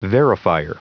Prononciation du mot verifier en anglais (fichier audio)
Prononciation du mot : verifier